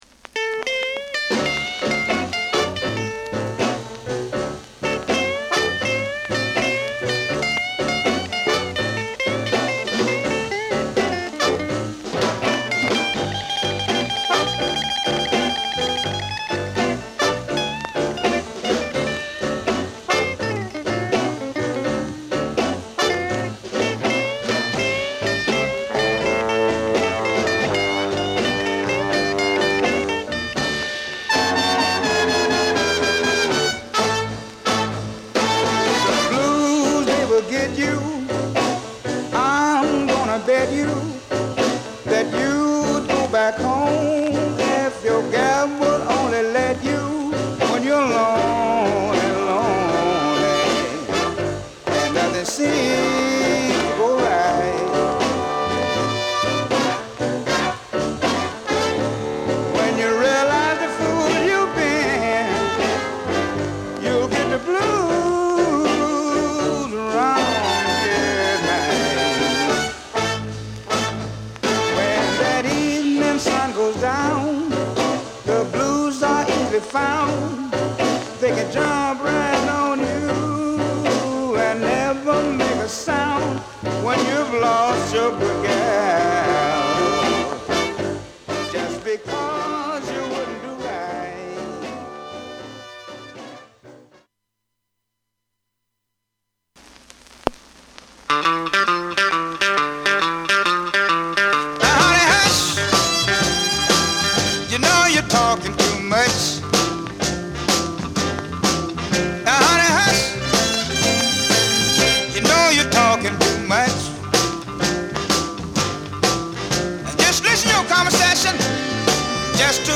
SIDE-Bは重量感のあるグルーヴィ・ブルース!!画像クリックで試聴出来ます(SIDE-B→SIDE…